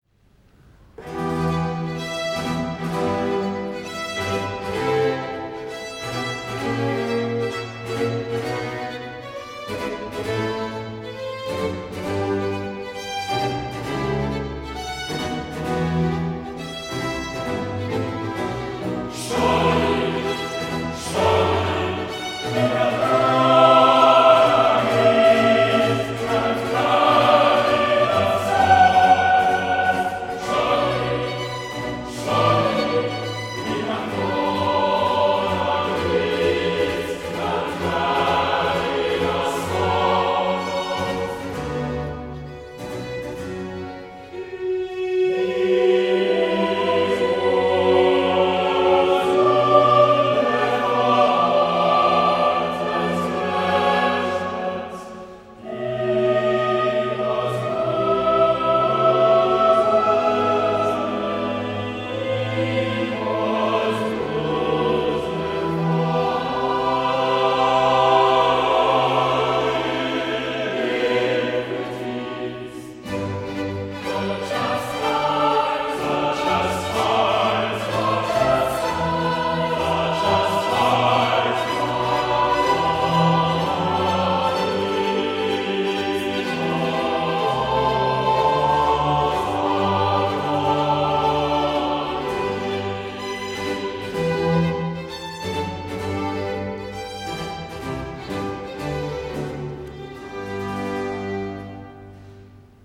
Chours